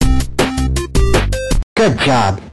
penny haze and ivy voice lines
good_job_ivy.ogg